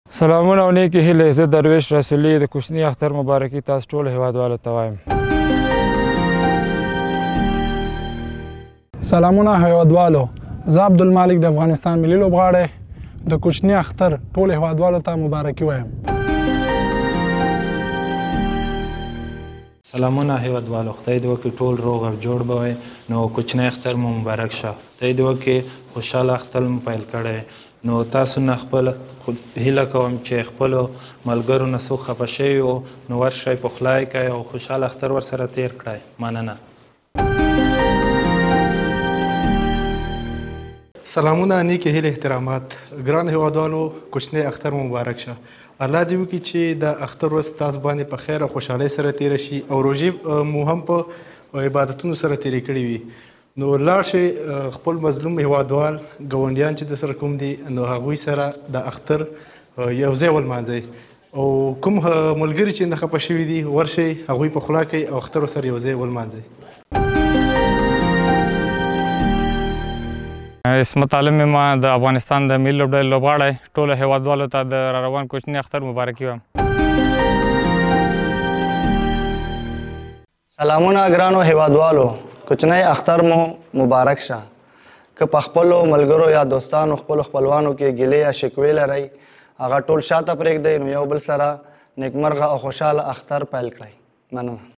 د افغانستان د کرېکټ د یو شمېر لوبغاړو اختريز پيغامونو دلته اورېدلی شئ.